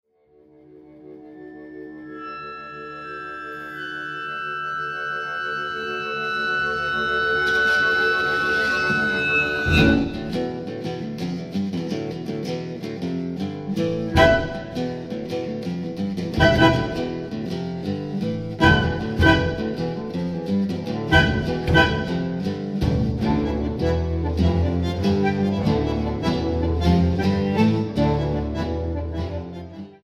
bandoneón